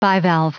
Prononciation du mot bivalve en anglais (fichier audio)
Prononciation du mot : bivalve